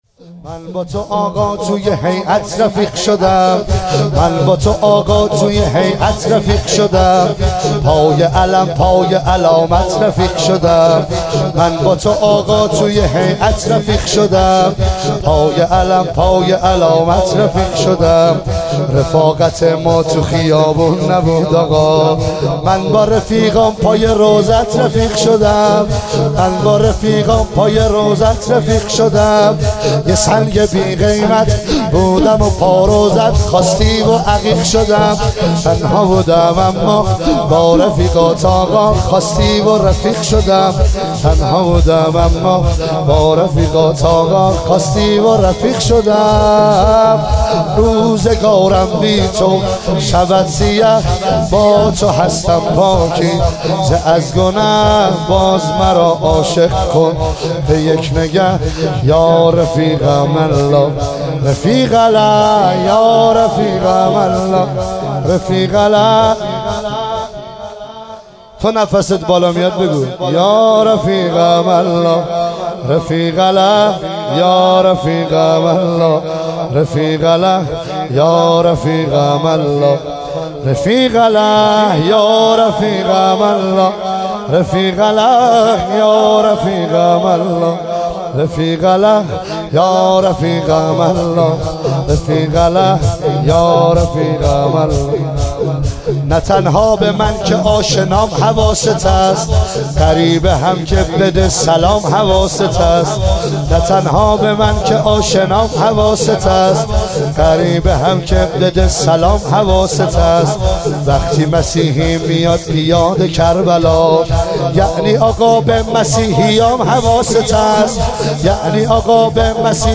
شب 13 محرم 1398 – ساری
زمینه السلام علی الحسین